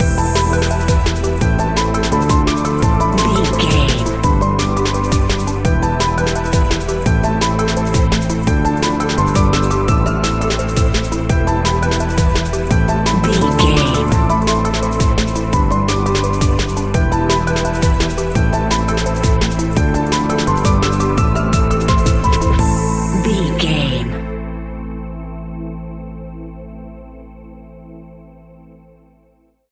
Aeolian/Minor
Fast
futuristic
frantic
driving
energetic
hypnotic
industrial
dark
drum machine
electric piano
synthesiser
sub bass
instrumentals